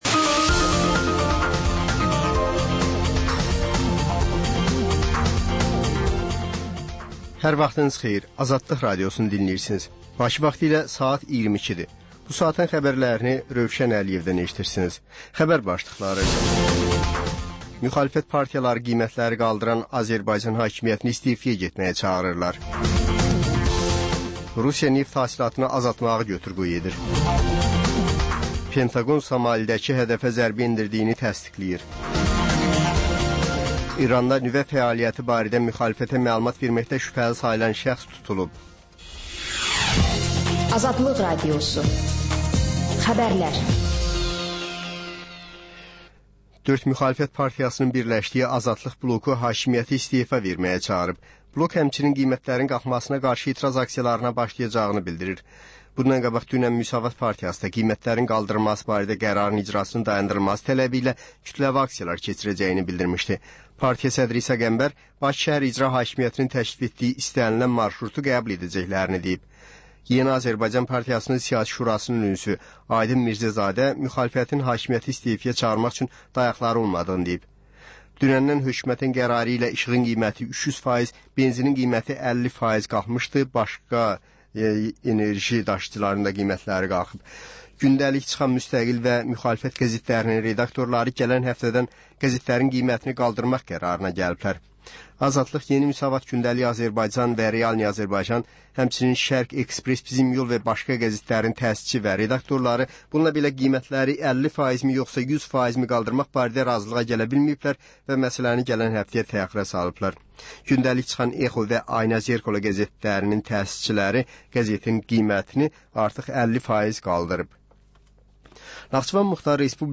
Xəbərlər, reportajlar, müsahibələr. Və: Şəffaflıq: Korrupsiya barədə xüsusi veriliş.